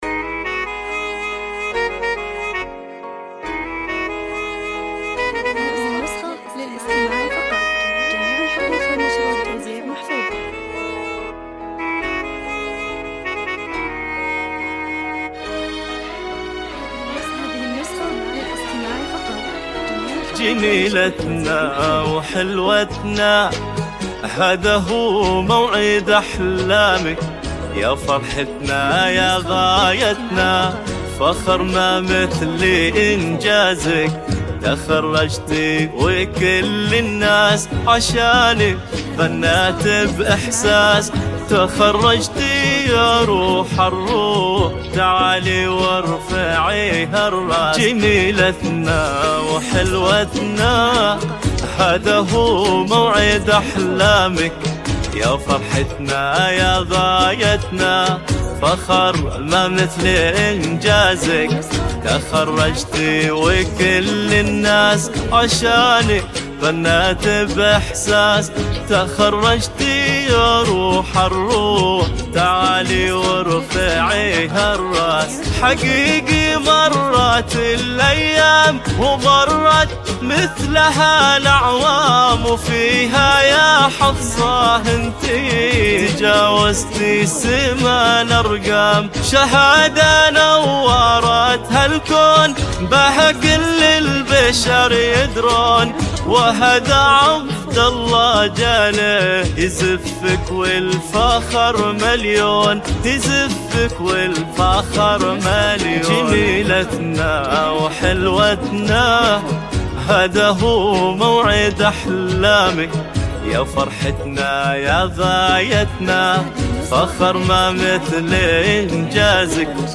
زفة تخرج موسيقية مميزة بتوزيع راقٍ